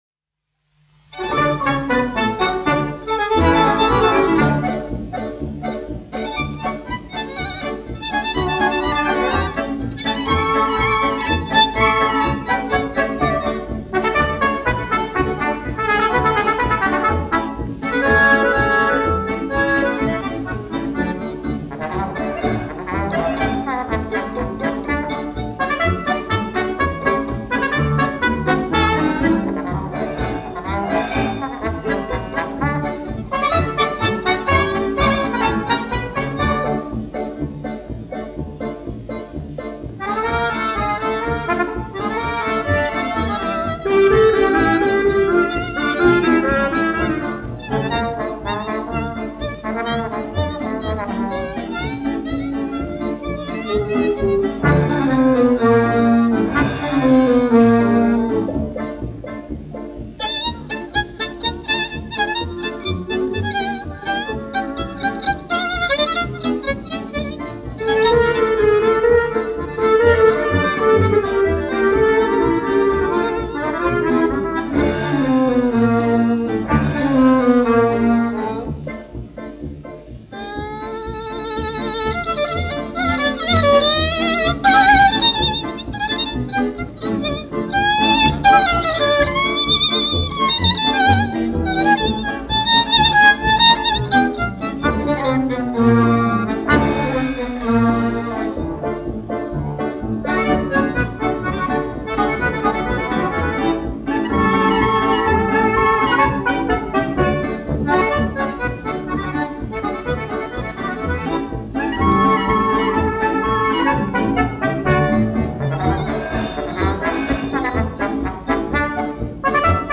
1 skpl. : analogs, 78 apgr/min, mono ; 25 cm
Fokstroti
Populārā instrumentālā mūzika
Latvijas vēsturiskie šellaka skaņuplašu ieraksti (Kolekcija)